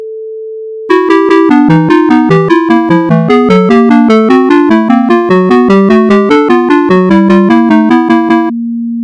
Sound sonification for Australia and Austria anual GDP growth